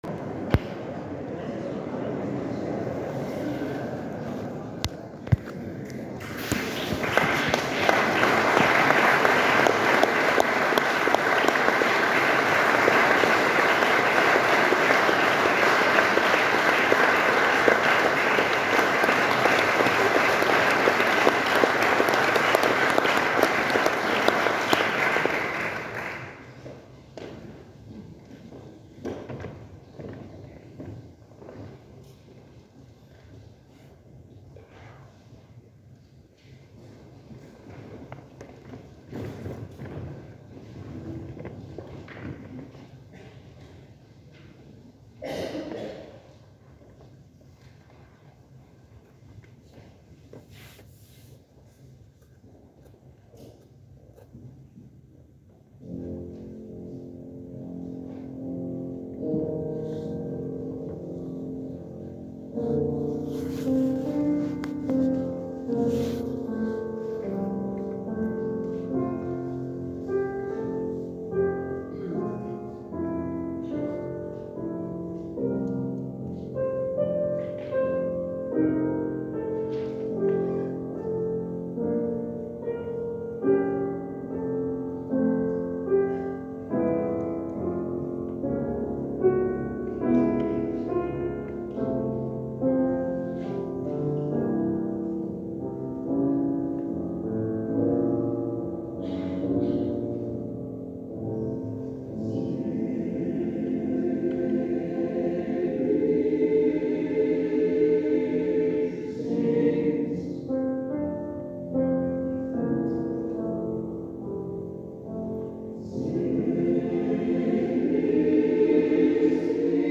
Ein deutsches Requiemconcert op 1 november 2025